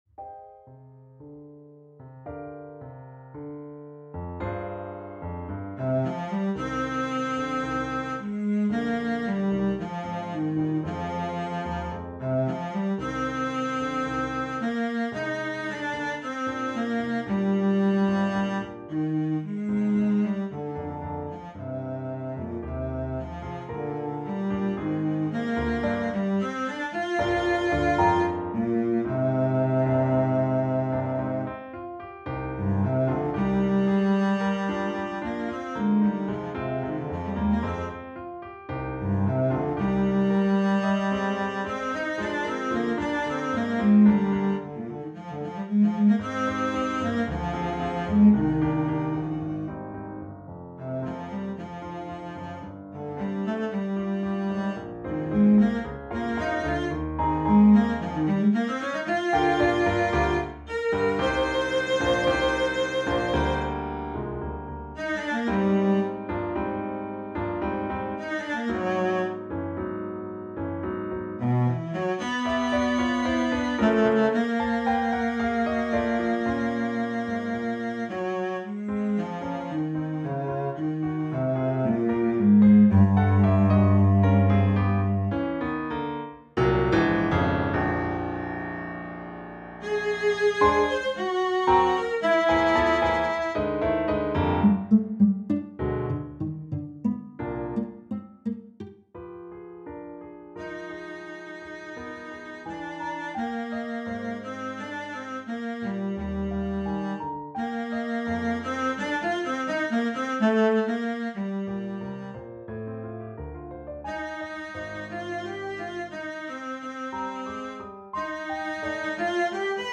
for Cello & Piano
A mildly melancholy – but hopefully not dispiriting – piece.